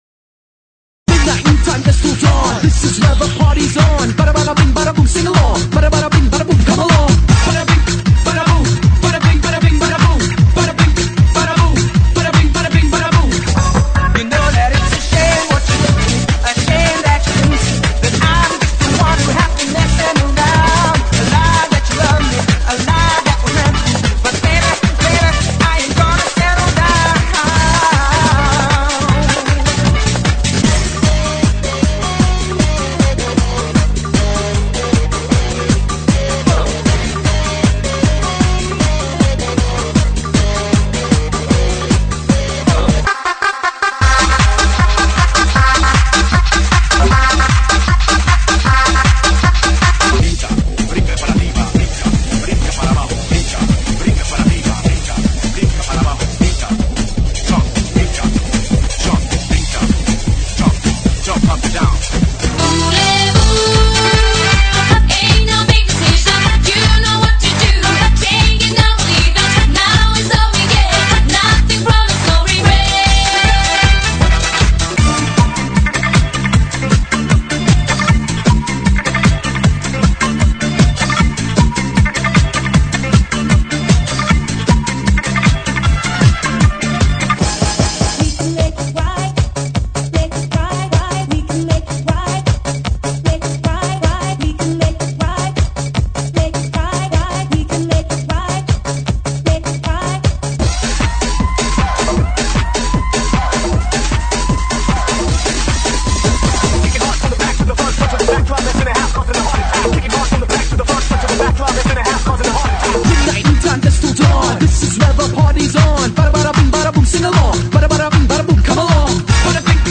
GENERO: DANCE – RETRO
DANCE RETRO,